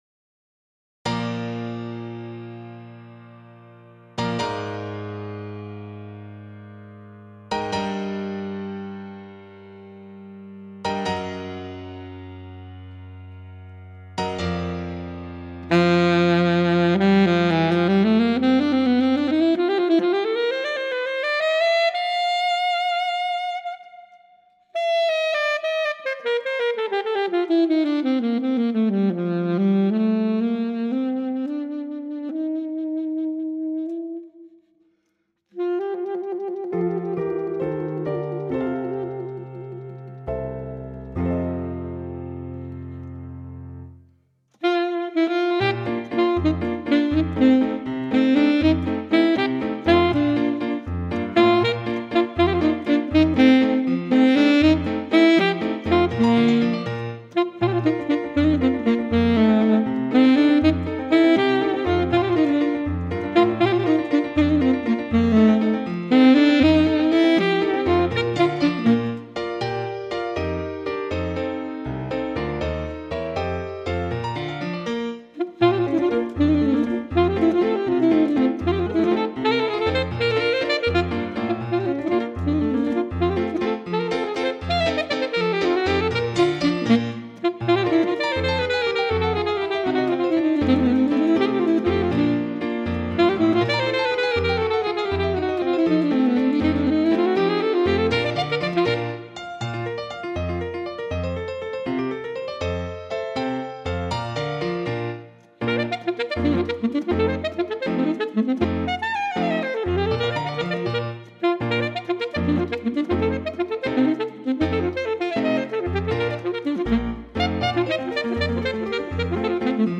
for Alto Saxophone & Piano
Alto Sax Range: C1 to F#3